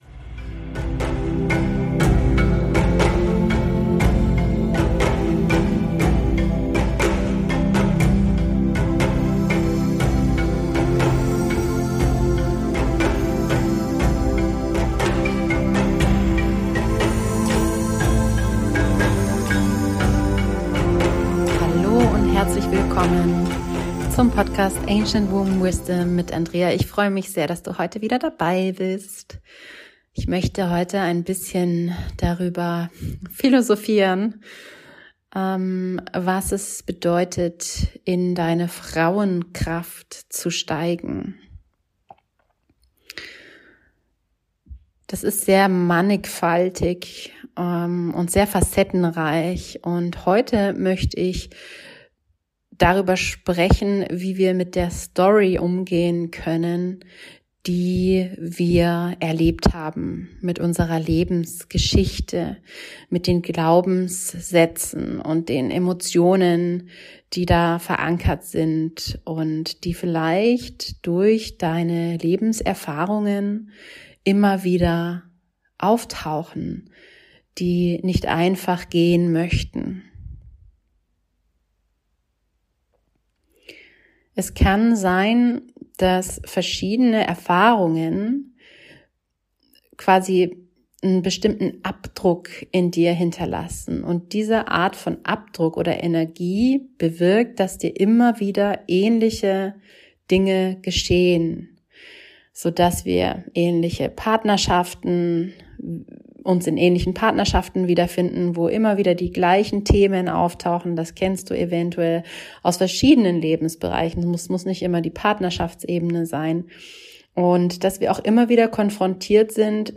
In diesem Podcast nehme ich Dich mit auf eine Reise zur Medizin der Schlange. Ich führe Dich durch eine Meditation und beschreibe Dir ein Ritual, das Dich die Dinge und Faktoren abstreifen lässt, die Dich zurückhalten.